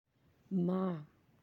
(maa’)